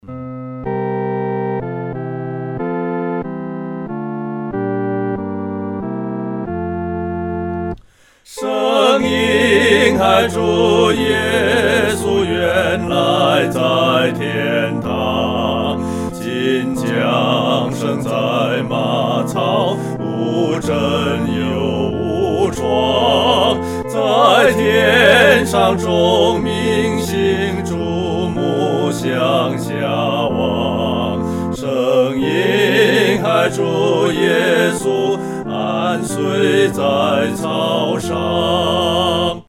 合唱（二声部）
在马槽里-合唱（四声部）.mp3